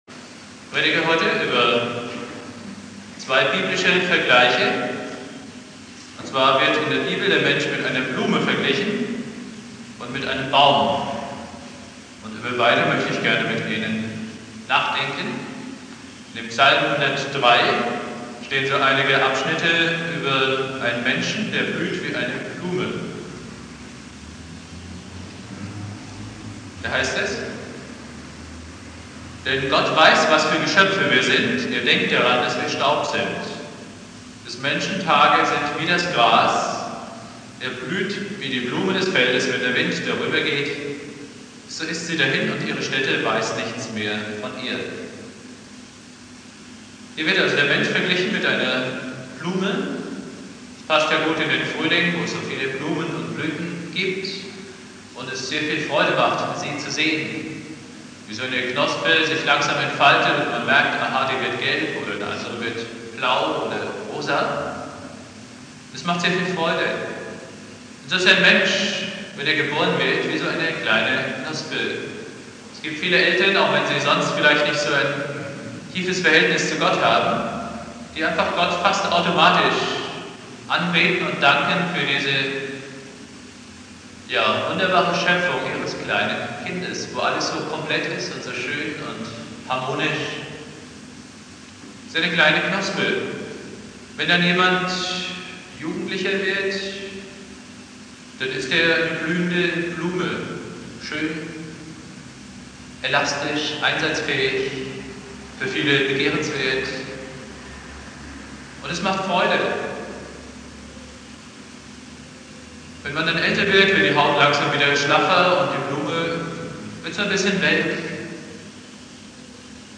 Predigt
Baum" (Friedhofskapelle) Bibeltext: Psalm 103,15-17 Dauer